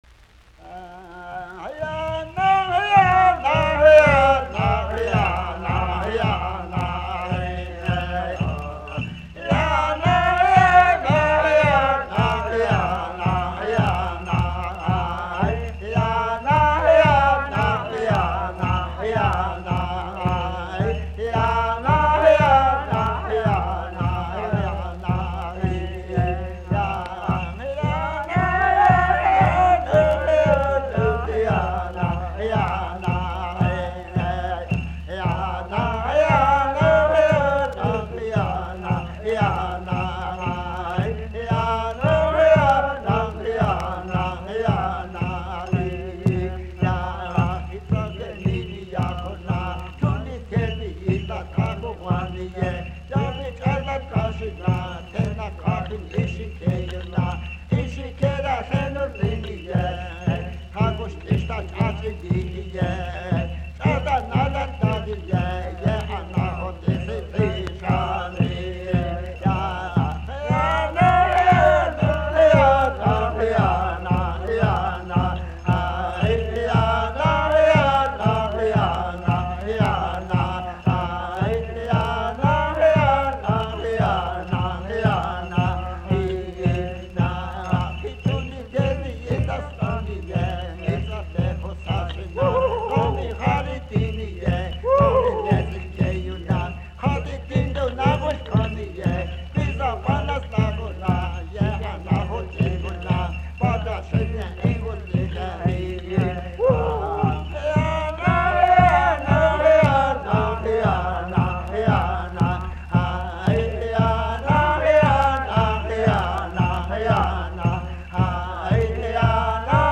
Recorded in Indian communities by Willard Rhodes, with the cooperation of the United States Office of Indian Affairs.